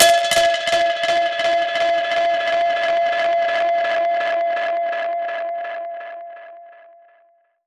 Index of /musicradar/dub-percussion-samples/125bpm
DPFX_PercHit_D_125-06.wav